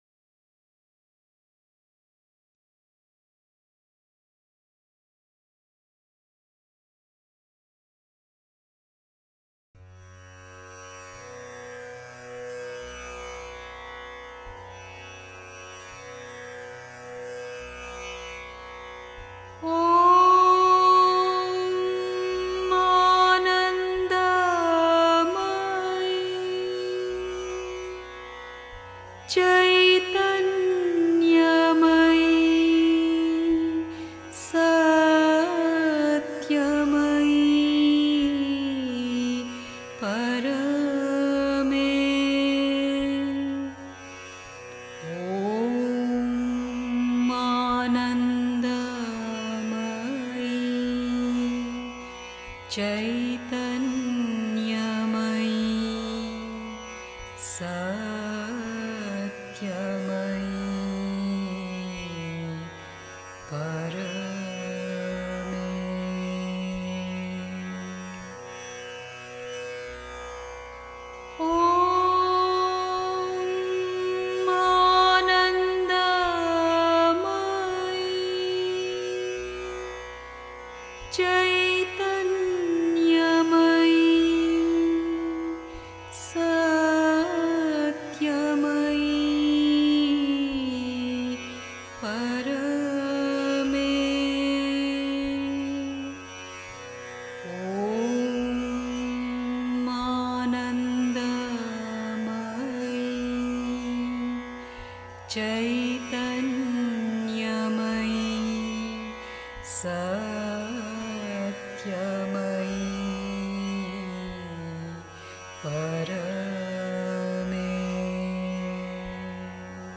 1. Einstimmung mit Musik. 2.
(Die Mutter, CWM, Vol. 16, pp. 315-16) 3. Zwölf Minuten Stille.